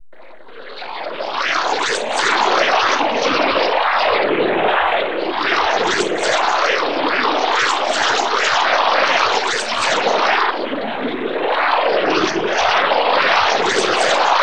Water Vortex Loop